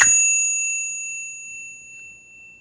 question_markTermékkör Forgófejes csengő
Kis méretű, de erőteles hangú csengő.
A forgófejes csengők fedelét bármelyik irányba forgatva fordulatonként kb. 6-8 csengő hangot adnak ki.